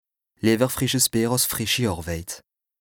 Français Dialectes du Bas-Rhin Dialectes du Haut-Rhin Page